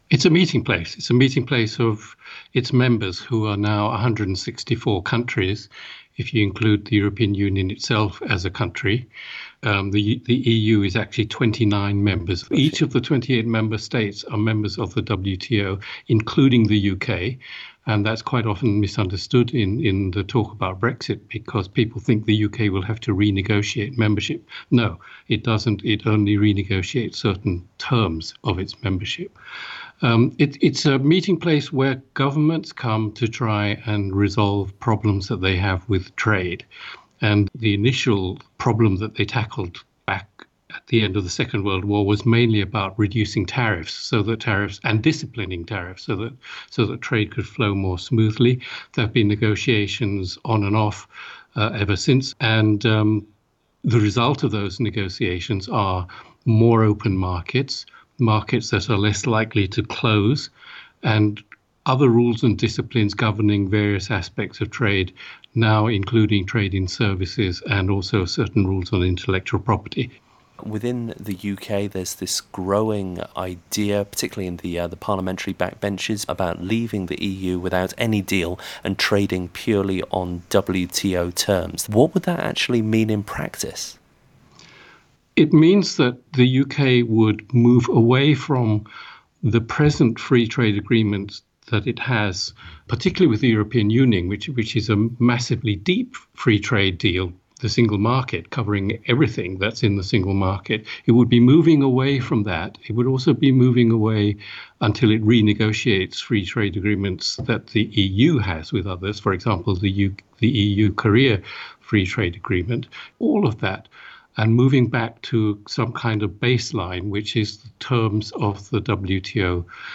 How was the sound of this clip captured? Speaking from Switzerland, he explains what that would really mean.